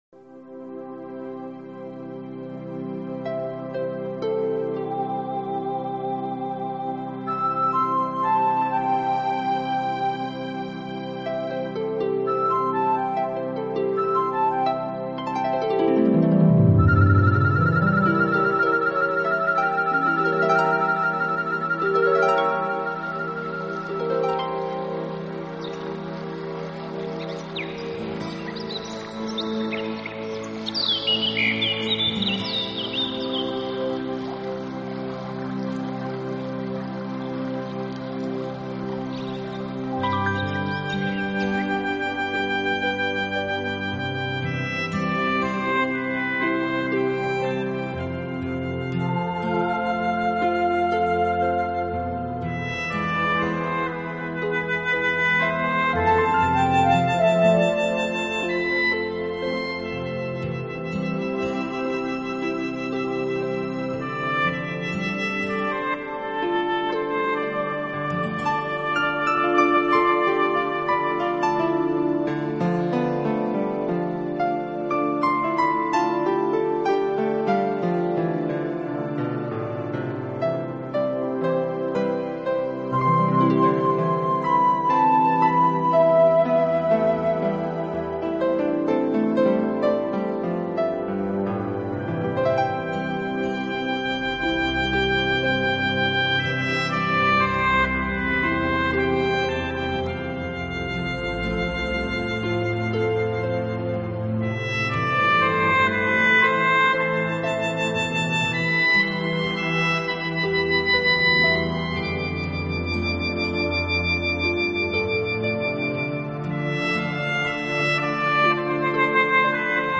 艺 术 家：  纯音乐
音乐流派：  Nature Music